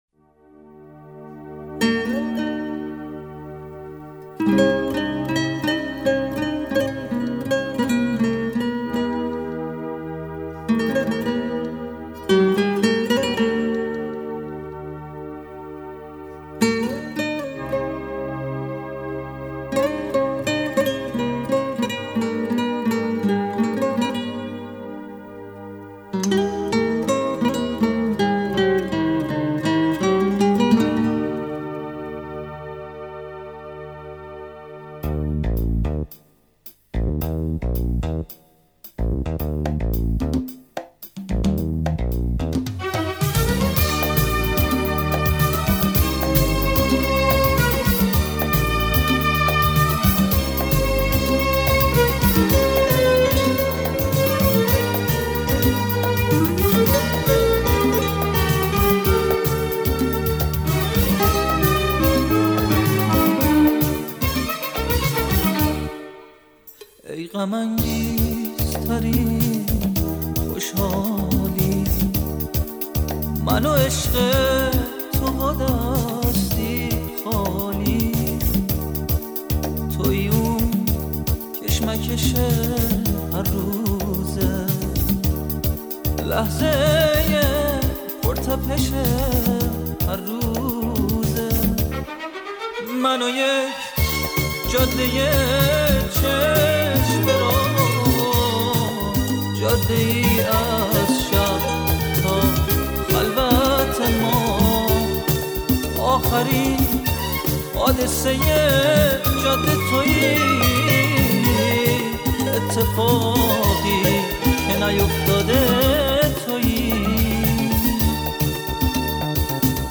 در سبک پاپ
ویلن (سلو)/گیتار
پیانو/کیبورد
گیتار الکتریک
ضبط: استودیو پاپ